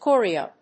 /ˈkɔriʌ(米国英語), ˈkɔ:ri:ʌ(英国英語)/